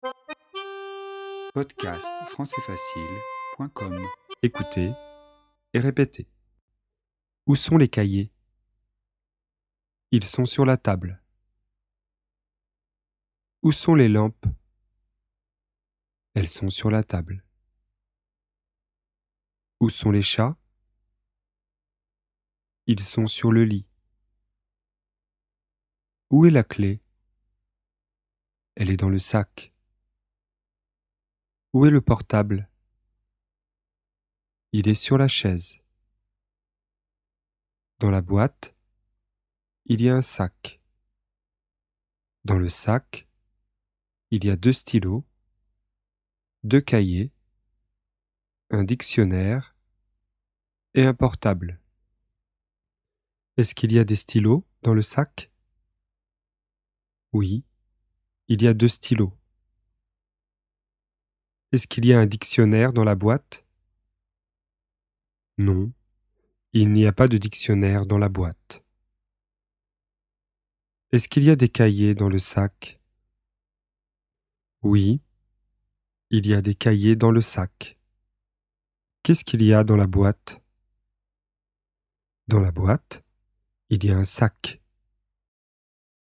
Cours sur les premiers pas en français, niveau débutant (A1), sur le thème situation dans l'espace.